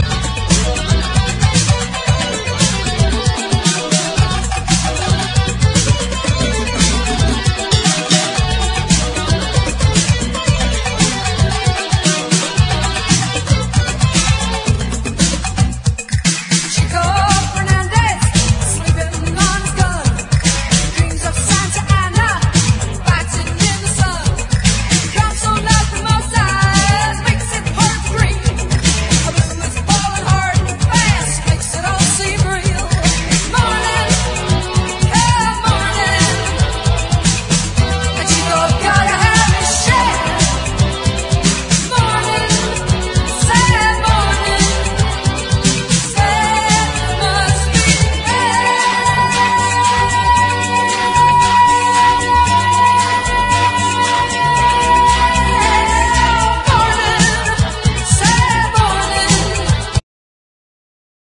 シャープなアーバン・ブギーに仕上がっています！